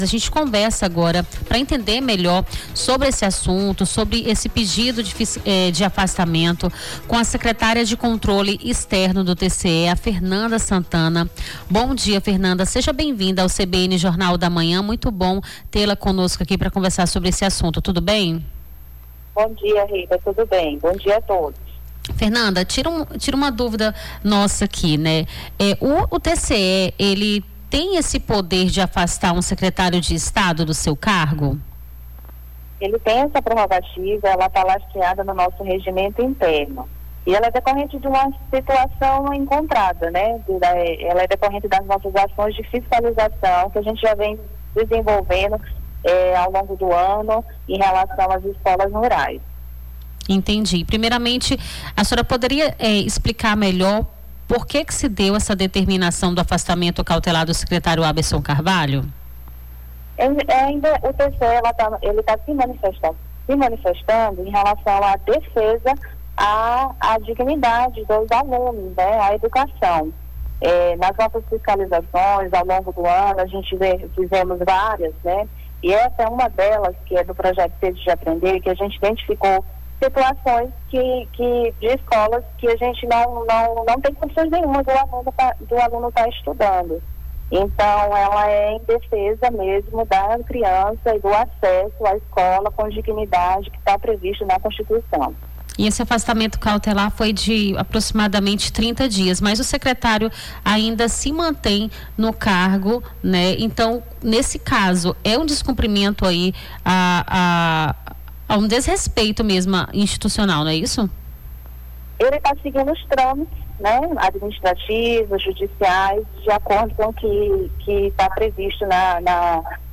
Nome do Artista - CENSURA - ENTREVISTA (REPERCUSSÃO ESCOLA RURAL - SECRETARIA DE CONTROLE EXTERNO TCE) 11-06-25.mp3